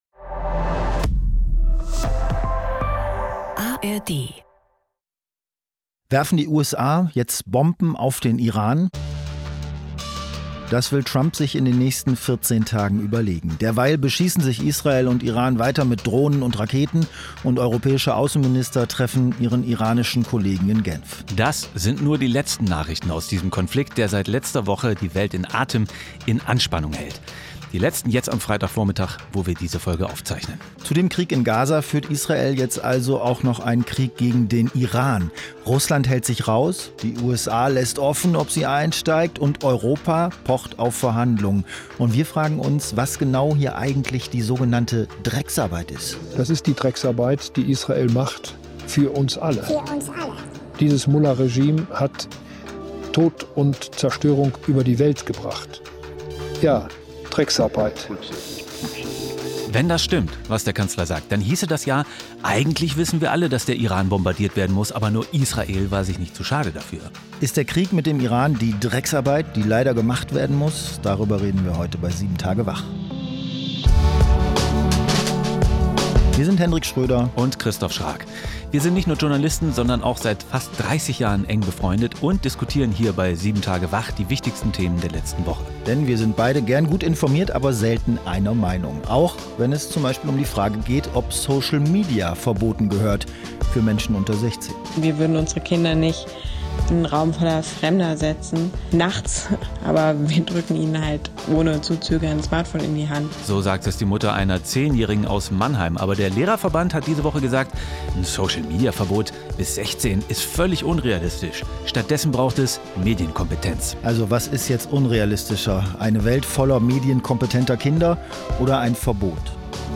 Zwei Freunde, zwei Meinungen, ein News-Podcast: